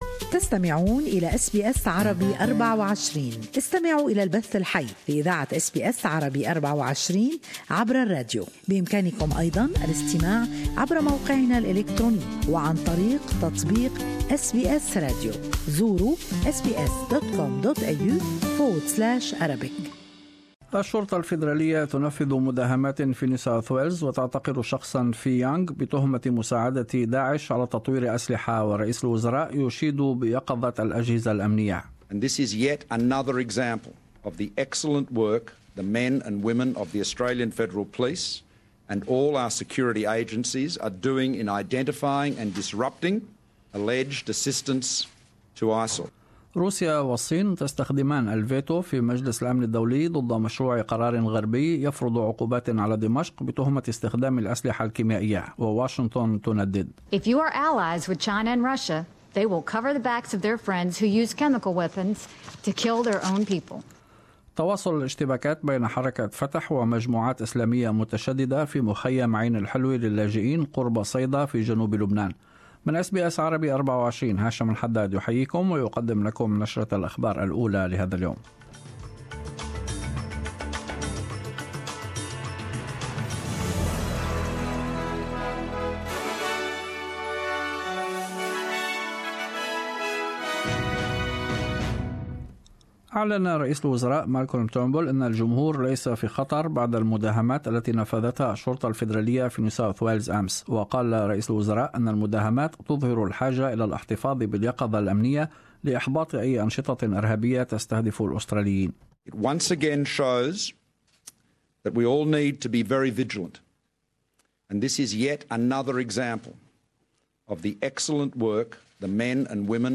News Bulletin 1-3-17